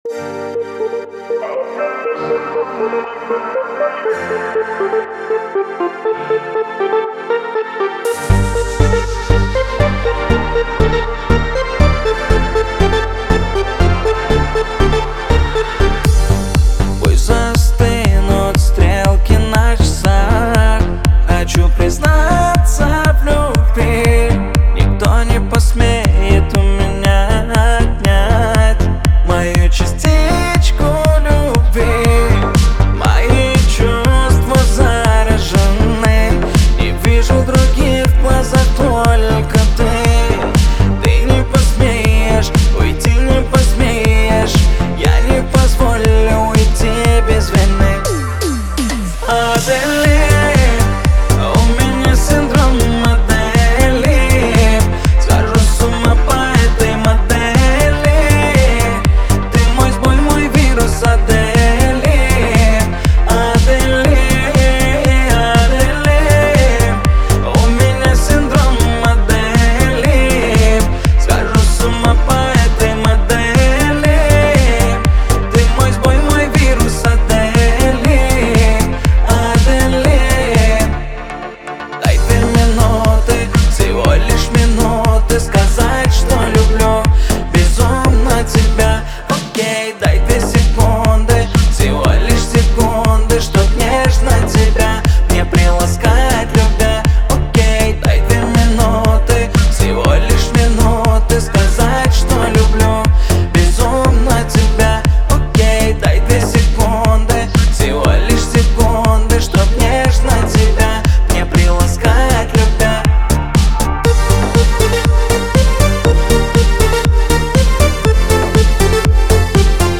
Качество: 320 kbps, stereo
Поп музыка, Поп про любовь